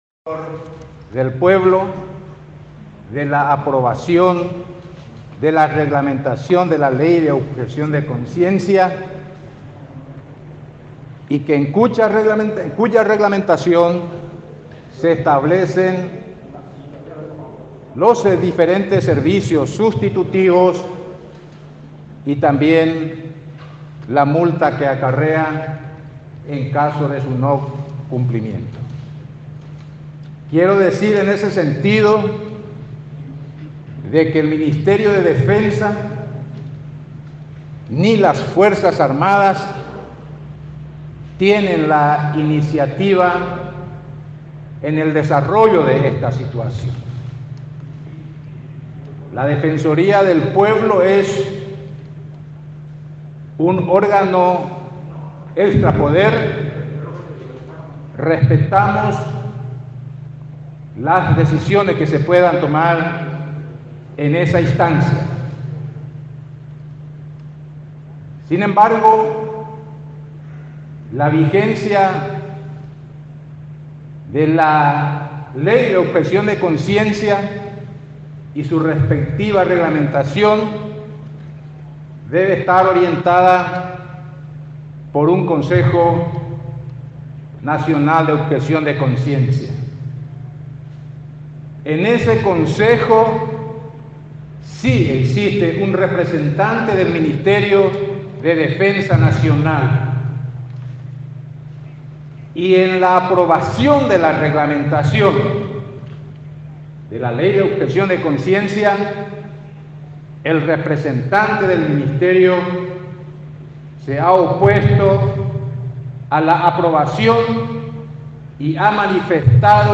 “Si bien es cierto que la Ley Nº 569/75 establece tasas (militares) a pagar, a las Fuerzas Armadas no les interesa la recaudación”, aseguró esta tarde en conferencia de prensa.